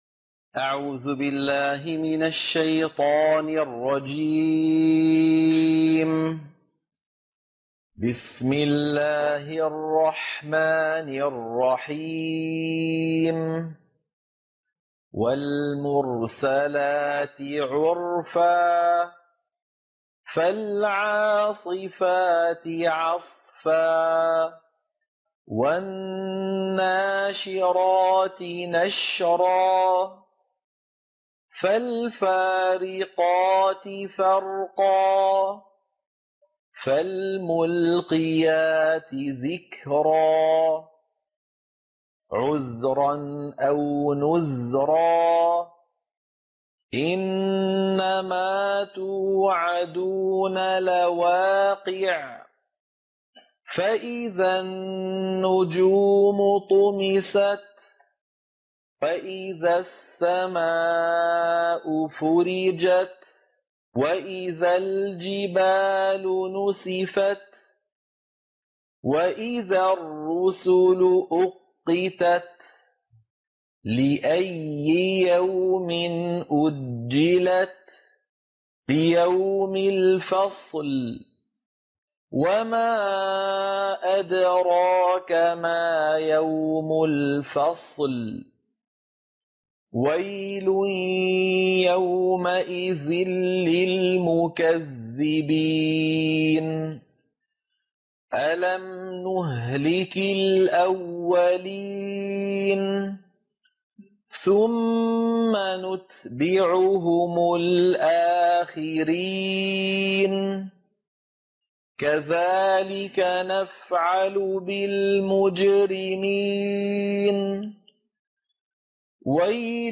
عنوان المادة سورة المرسلات - القراءة المنهجية